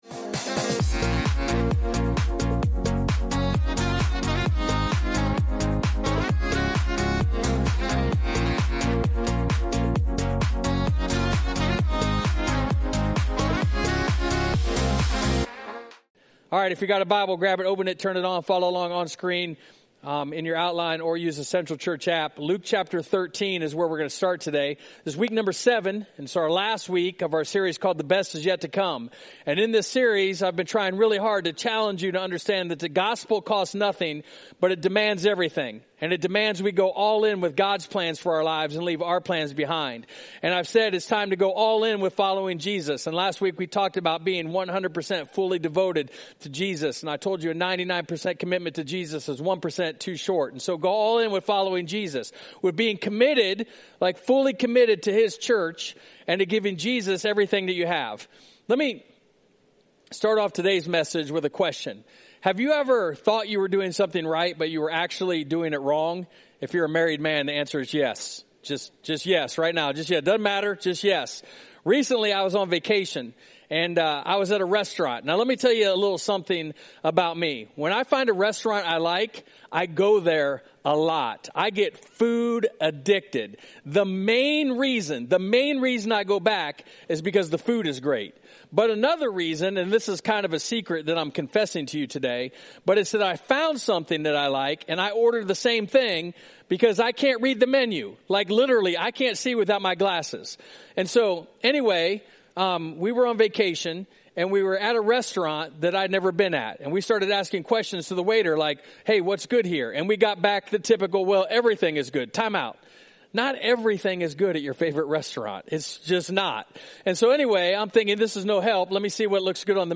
wrapped up our sermon series